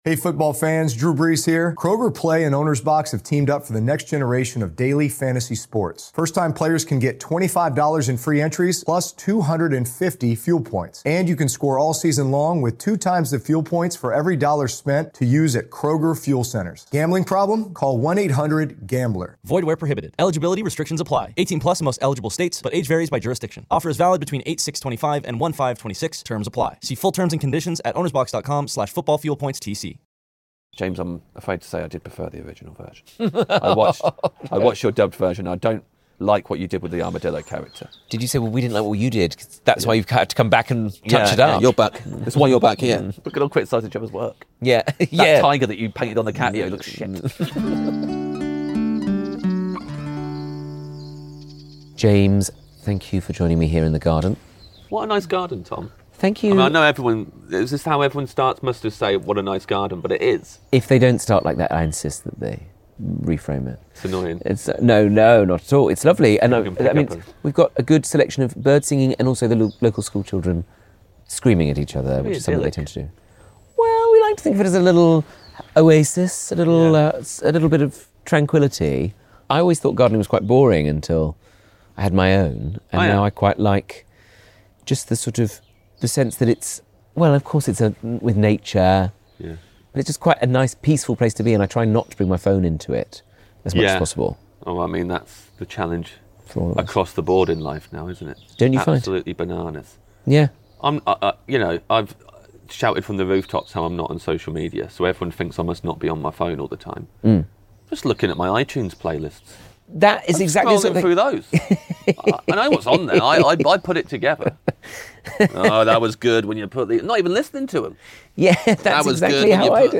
What a joy it was to be back in the garden for another episode of Pottering, this time with none other than James Acaster.